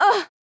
backdamage_flying.wav